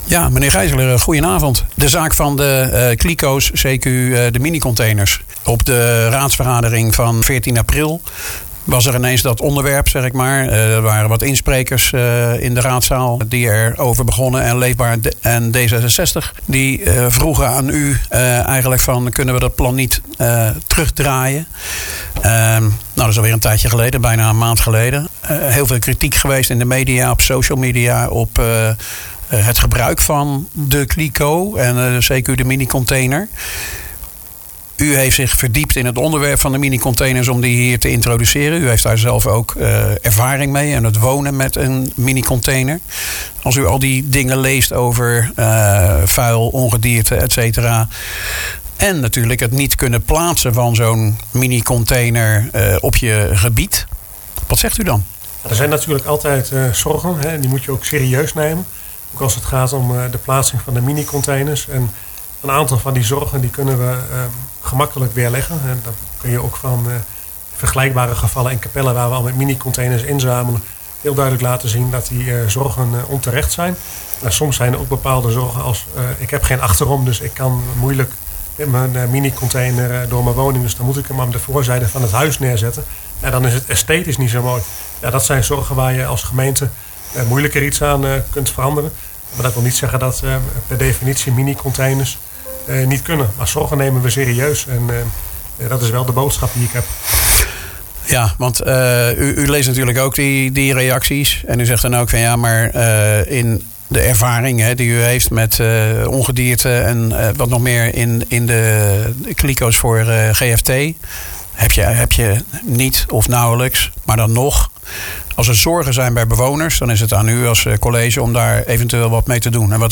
praat met wethouder Sjoerd Geissler over het afvalbeleid, de kritiek van bewoners en hoe verdere besluitvorming over minicontainers wel of niet dus over de verkiezingen getild wordt.